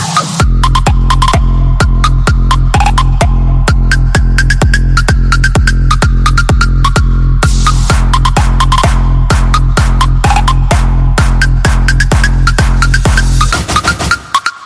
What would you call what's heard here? Kategorien: Elektronische